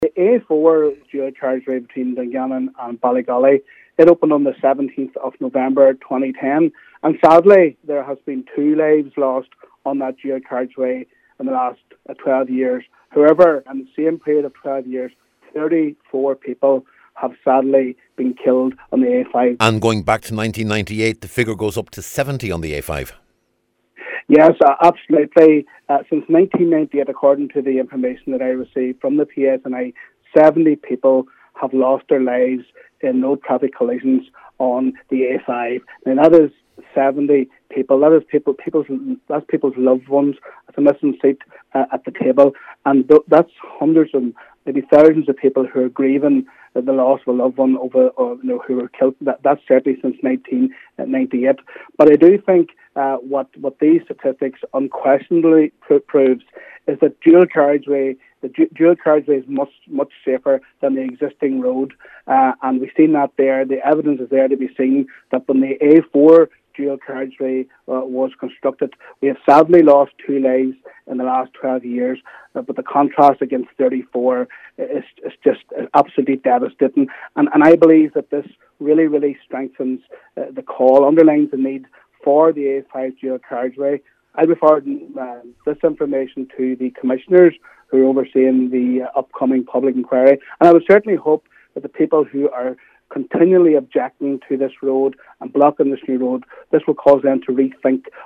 Declan McAleer says the difference in death rates between the A4 dual carriageway and the A5 road is compelling evidence that dual carriageways are safer……………